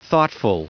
Prononciation du mot thoughtful en anglais (fichier audio)
Prononciation du mot : thoughtful